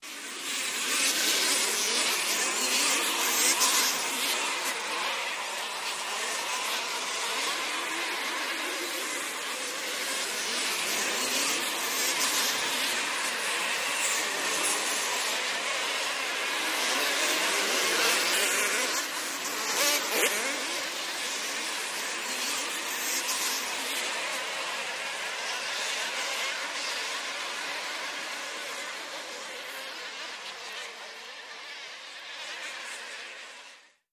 Animals-insects Bg|Bees | Sneak On The Lot